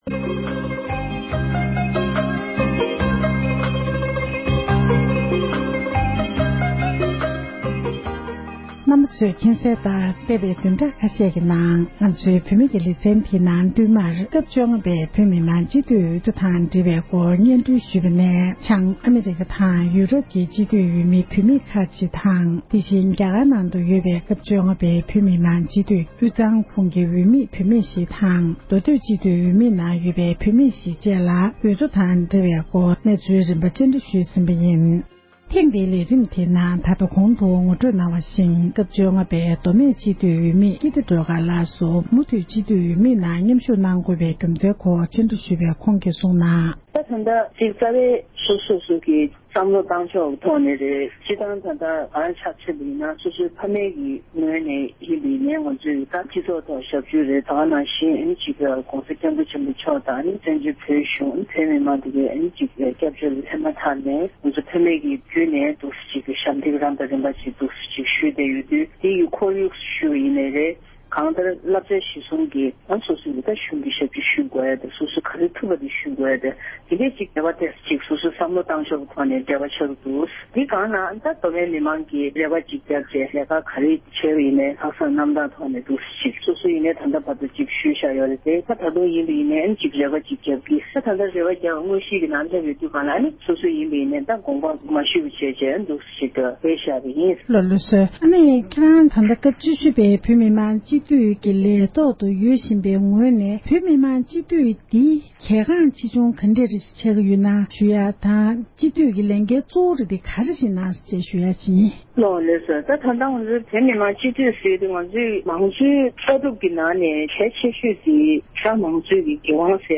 གནས་འདྲི་ཞུས་པ་ཞིག་ལ་གསན་རོགས་ཞུ༎